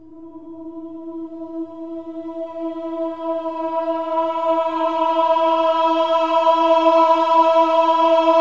OH-AH  E4 -L.wav